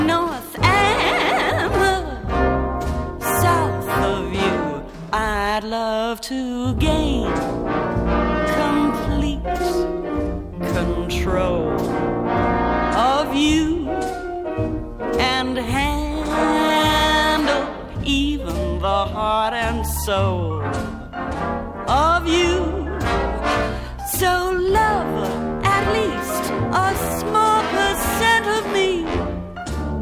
Musiques d'influence afro-américaine - Jazz